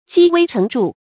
积微成著 jī wēi chéng zhù 成语解释 微：细微；著：显著。微不足道的事物，经过长期积累，就会变得显著。
成语简拼 jwcz 成语注音 ㄐㄧ ㄨㄟ ㄔㄥˊ ㄓㄨˋ 常用程度 常用成语 感情色彩 中性成语 成语用法 作谓语、宾语；指积少成多 成语结构 动宾式成语 产生年代 古代成语 近 义 词 积少成多 、 积微致著 成语例子 南朝·宋·何承天《上历新法表》：“自然有毫末之差，连日累月， 积微成著 。”